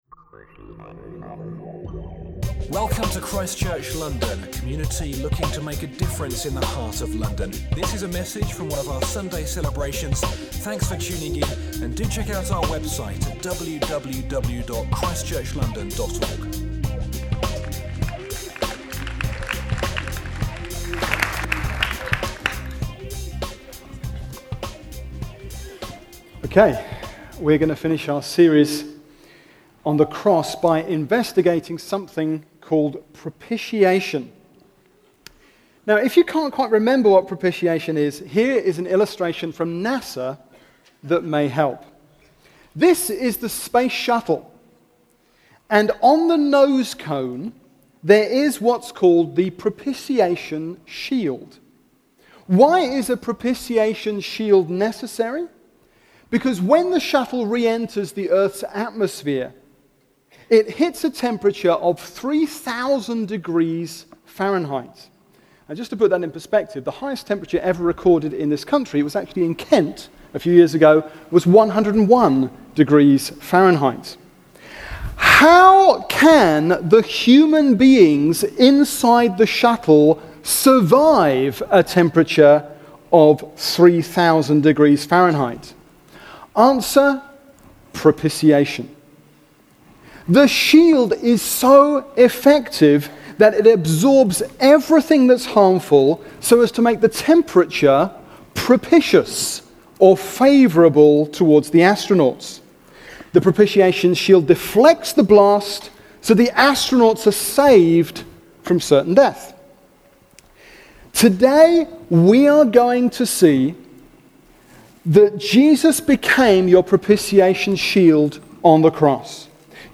Romans 3: 21-26 – Preaching from ChristChurch London’s Sunday Service